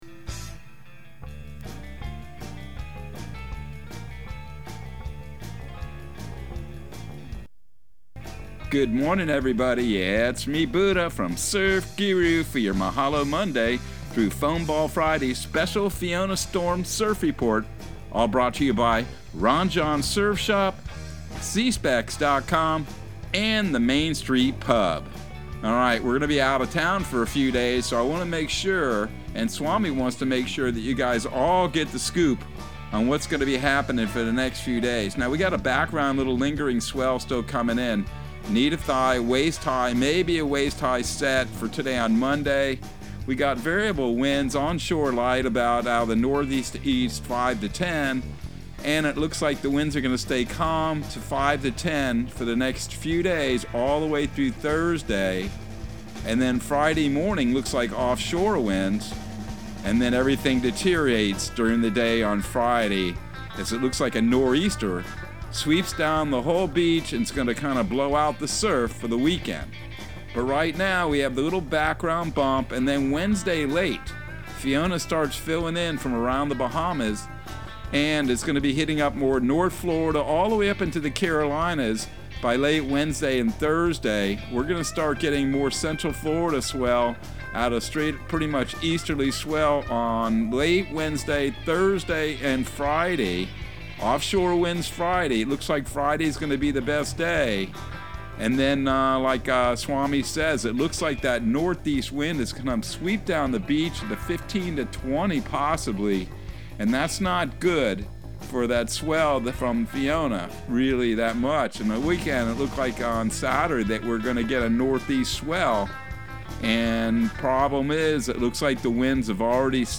Surf Guru Surf Report and Forecast 09/19/2022 Audio surf report and surf forecast on September 19 for Central Florida and the Southeast.